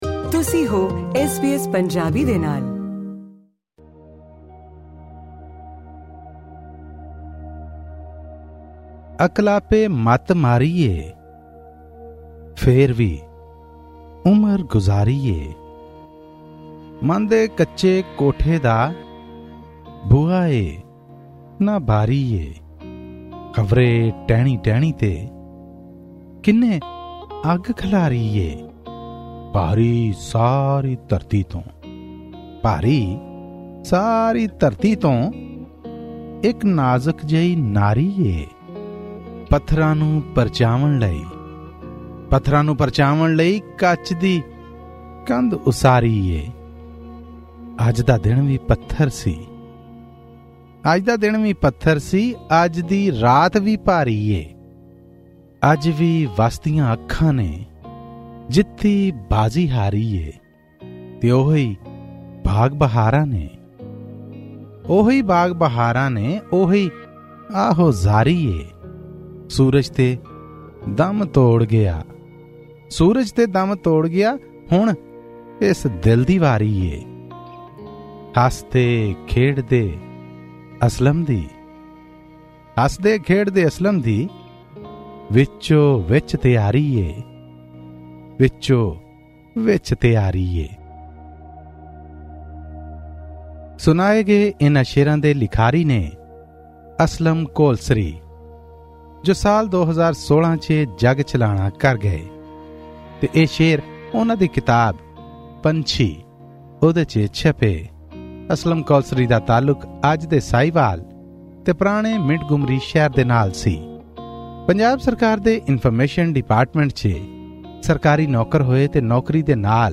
Pakistani Punjabi poetry book review: 'Panchhi' by Aslam Kolsari